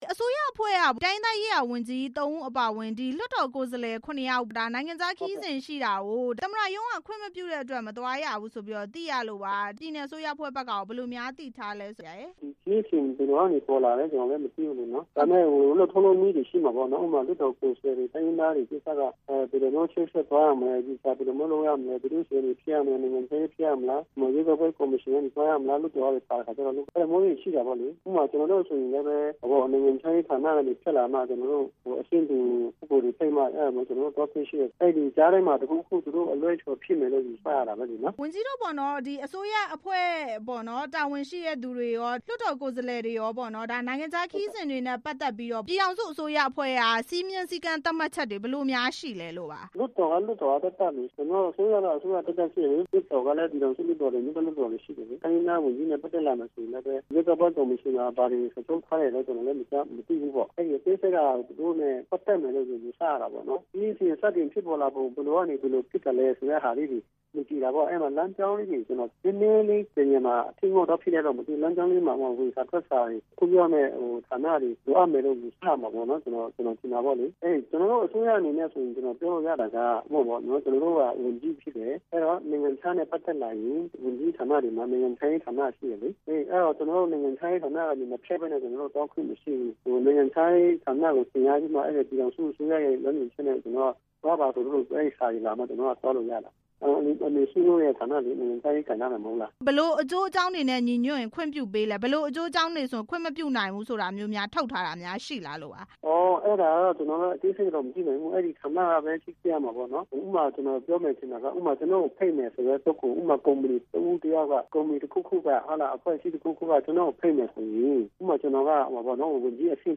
ဝန်ကြီးတွေ ပြည်ပခရီးစဉ် သွားရောက်ခွင့်မရတဲ့အကြောင်း မေးမြန်းချက်